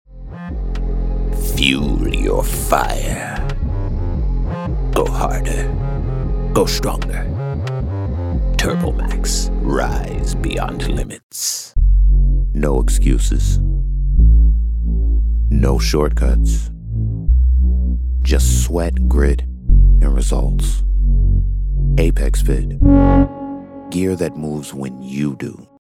Style in every syllable. Depth in tone.
Online Ad Demo